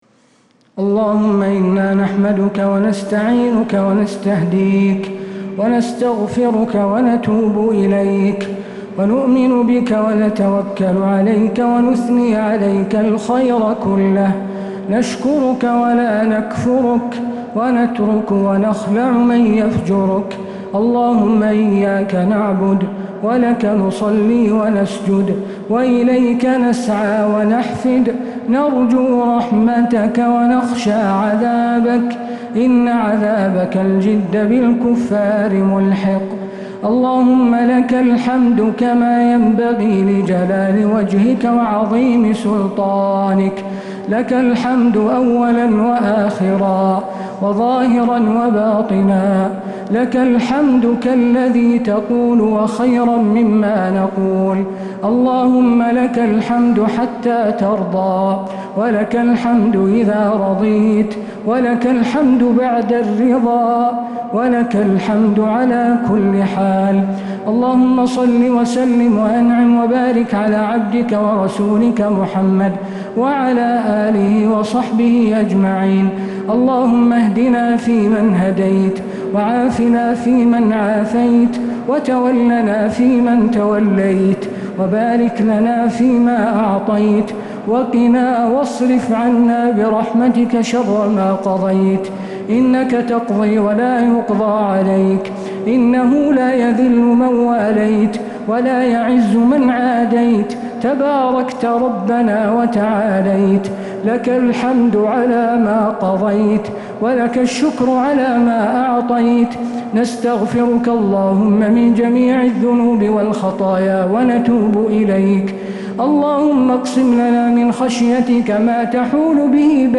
دعاء القنوت ليلة 29 رمضان 1446هـ | Dua 29th night Ramadan 1446H > تراويح الحرم النبوي عام 1446 🕌 > التراويح - تلاوات الحرمين